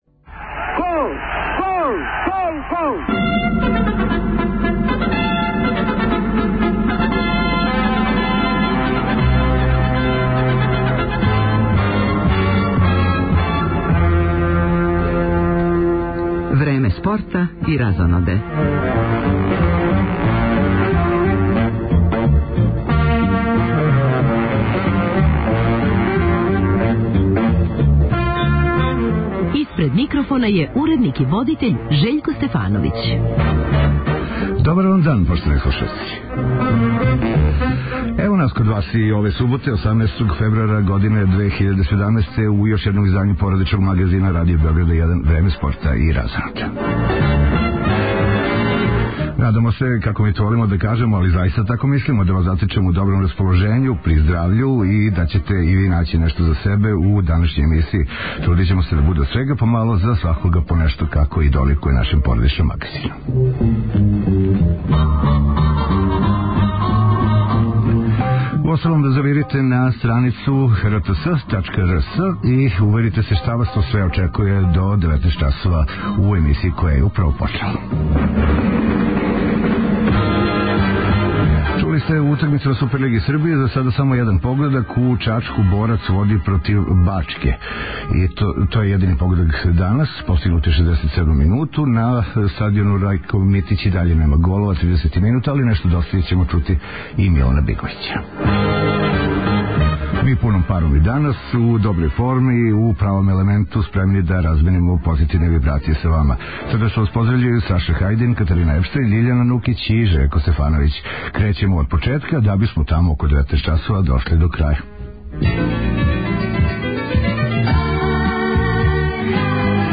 Током емисије наши репортери јављаће се са фудбалске утакмице Црвена звезда- Нови Пазар и кошаркашког дуела ФМП- Црвена звезда. Пратићемо кретање резултата на утакмици одбојкашица Визура - Јединство, фудбалским дуелима Супер лиге Србије и значајнијих европских шампионата.